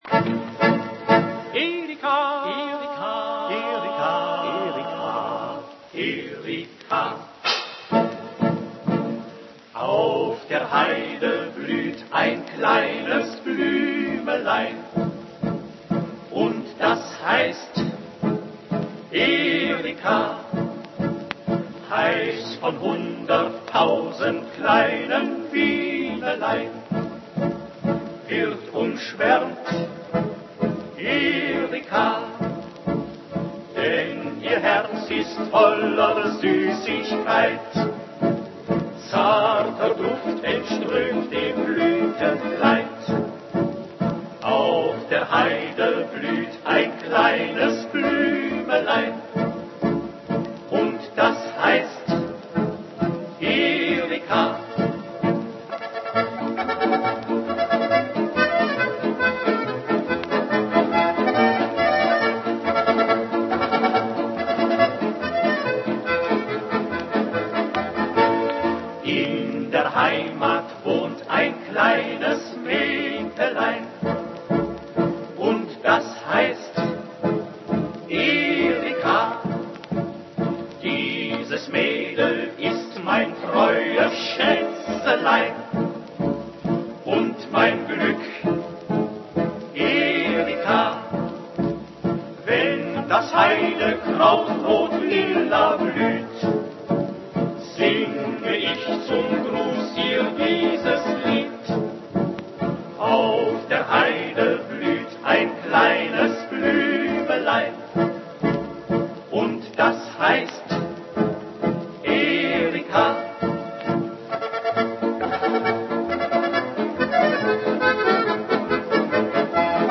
Когда слушаешь эту песню, то невольно содрогаешься от какой-то неотвратимой мощи её простой повторяющейся мелодии и от этого навязчивого рефрена — имени девушки, «Эрика».
Она была выполнена очень известным в 1930—40 годы немецким вокально-инструментальным коллективом — квартетом Эриха Хайна (Erich Heyn) — для одного из т. н. «концертов по заявкам вермахта» (Wünschkonzert № 2). Надо сказать, что «Эрика» приобретает здесь прямо-таки лирический и даже, можно сказать, задушевный характер — насколько это вообще возможно, ибо полностью замаскировать её «маршевость» оказывается не под силу даже этой знаменитой четвёрке музыкантов.